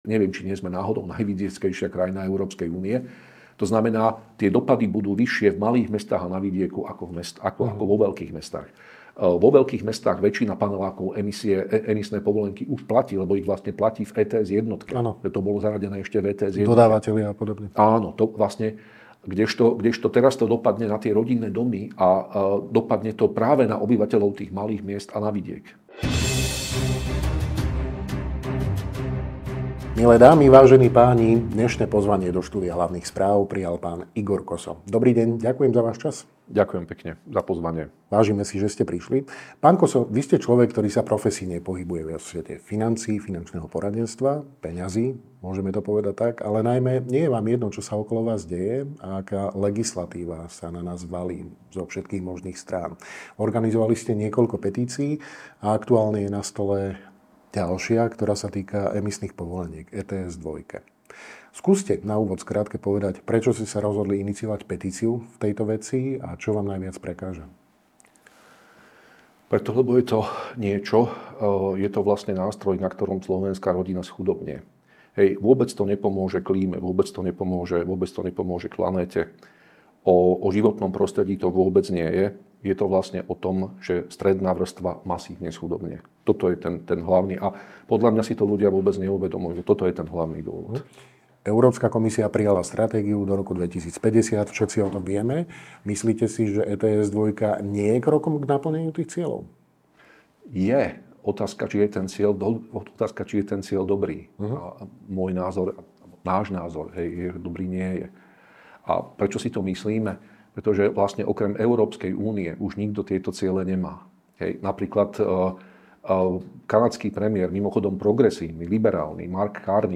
Dozviete sa vo videorozhovore